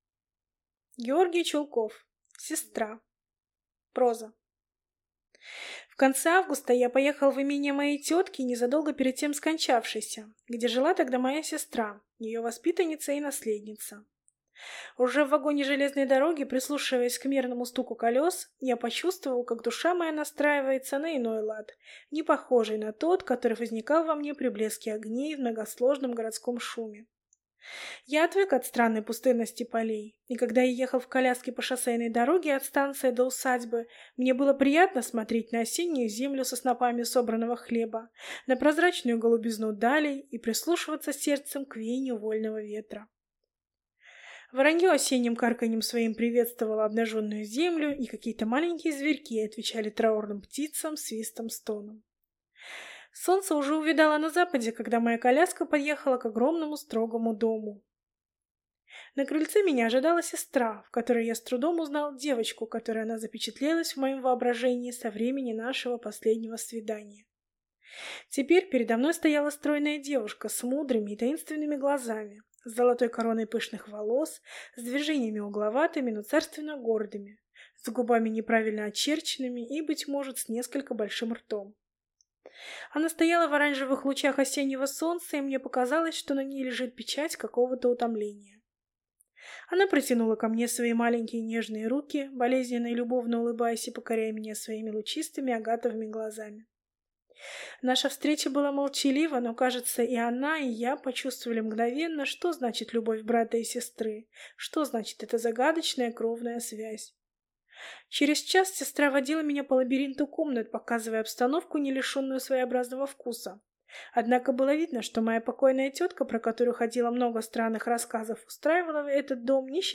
Аудиокнига Сестра | Библиотека аудиокниг